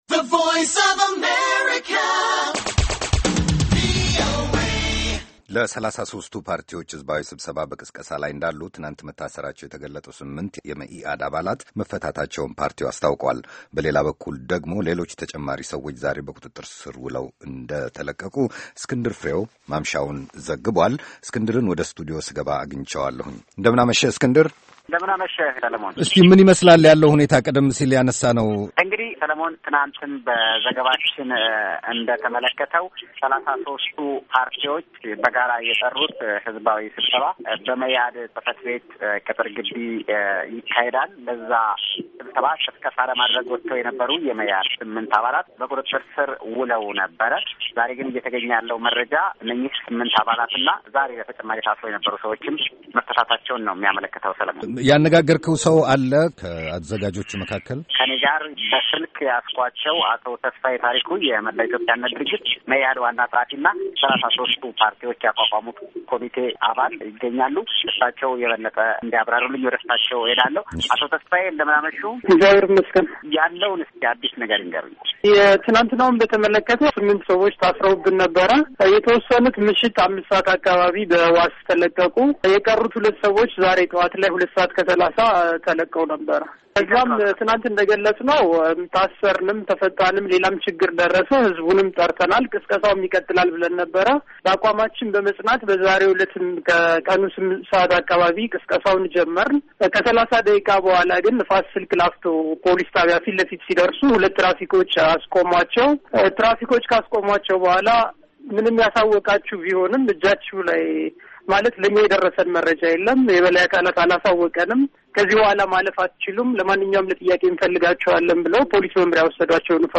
ዘገባ